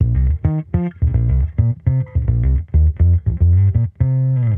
Index of /musicradar/sampled-funk-soul-samples/105bpm/Bass
SSF_PBassProc2_105C.wav